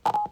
BrokenWindows1.ogg